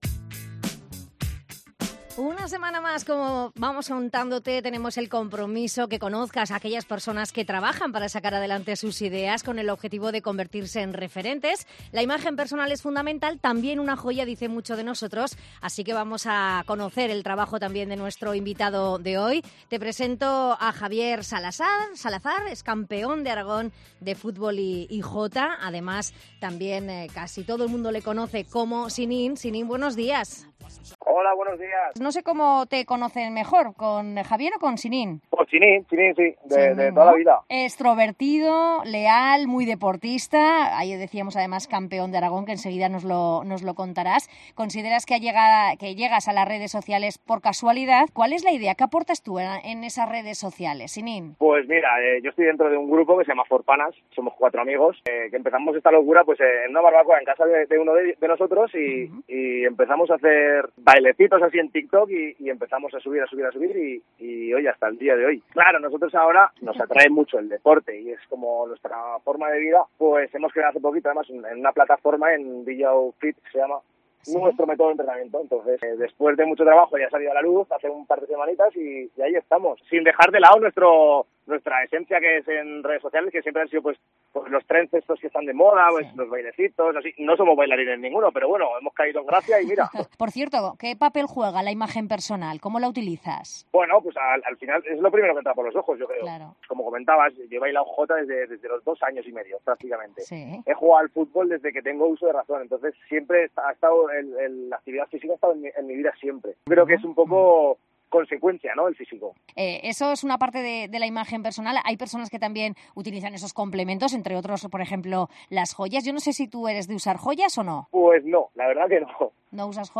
Hoy conversamos